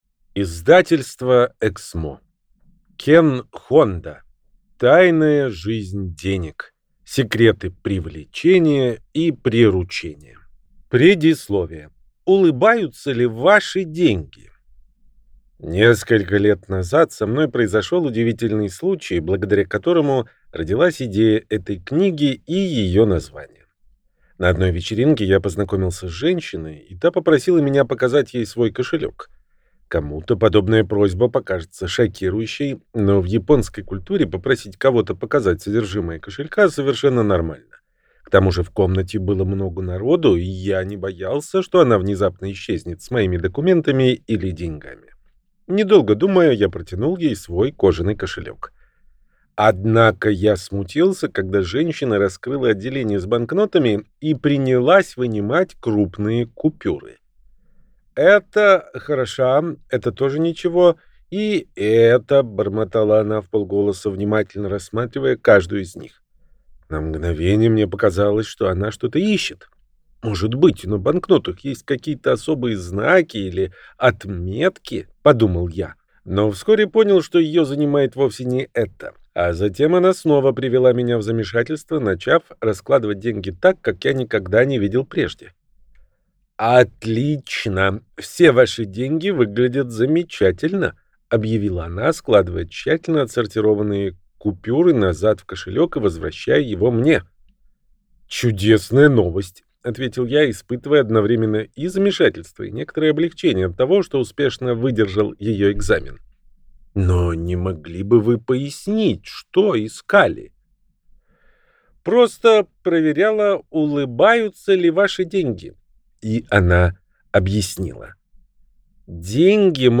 Аудиокнига Тайная жизнь денег. Секреты привлечения и приручения | Библиотека аудиокниг